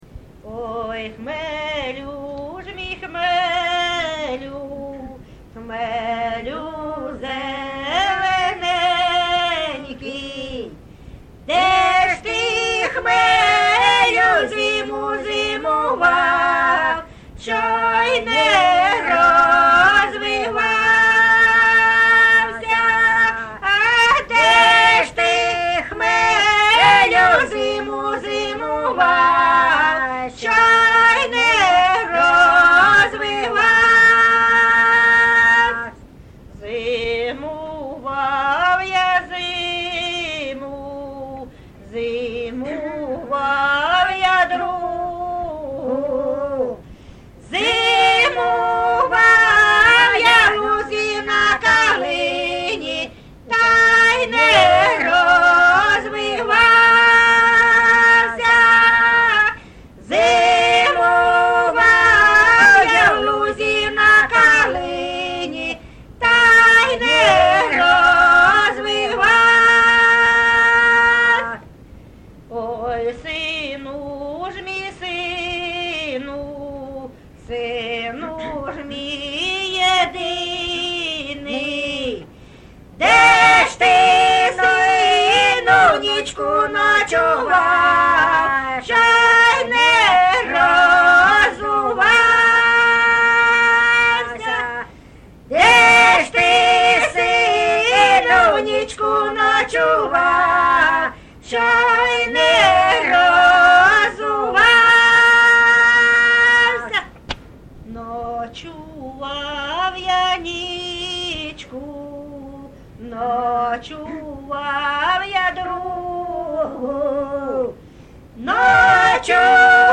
ЖанрПісні з особистого та родинного життя
Місце записус. Закітне, Краснолиманський (Краматорський) район, Донецька обл., Україна, Слобожанщина